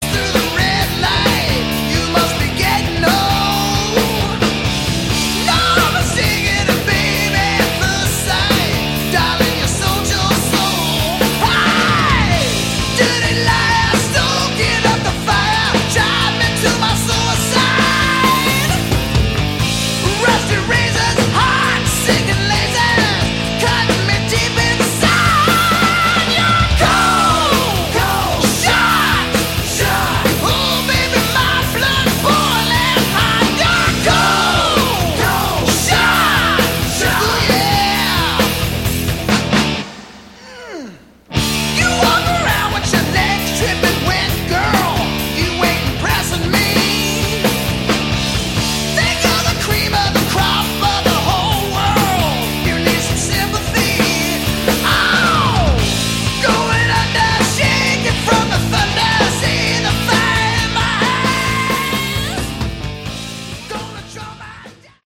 Category: Hard Rock
vocals
guitars
bass
drums
excellent hair metal music
strong guitar lines,big choruses